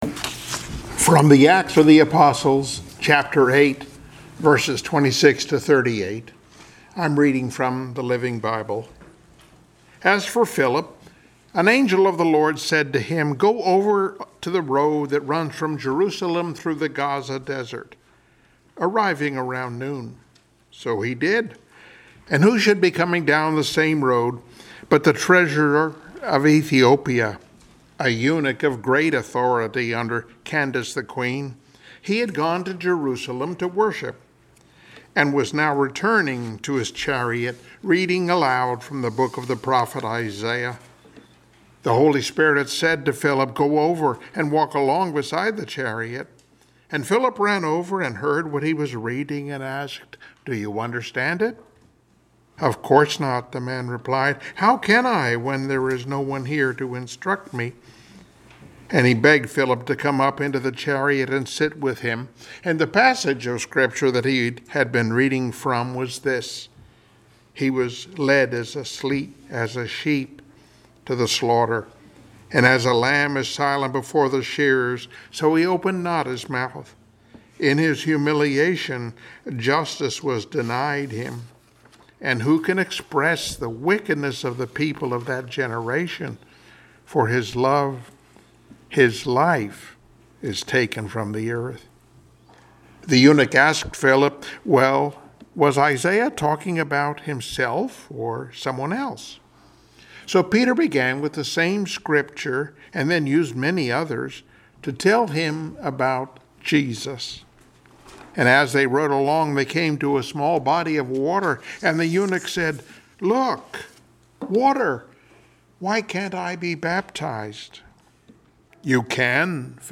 Passage: Mathew 28:19 Service Type: Sunday Morning Worship